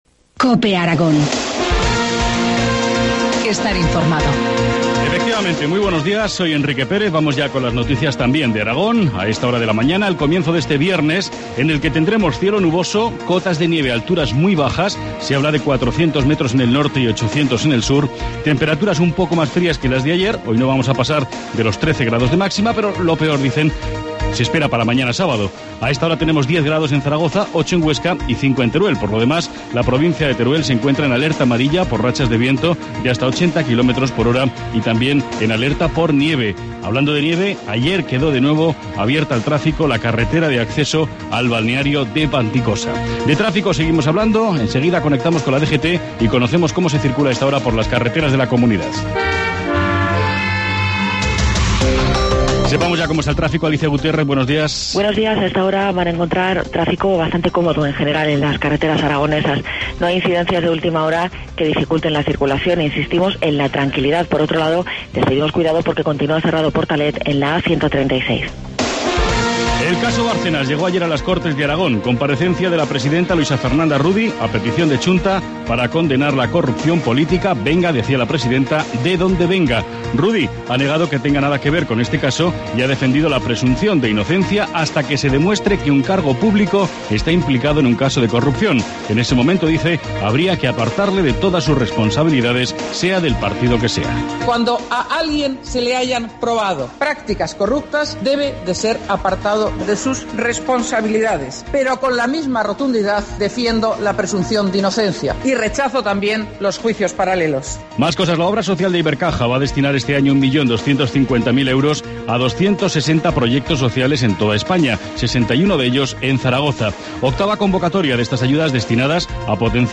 Informativo matinal, viernes 22 de febrero, 7.25 horas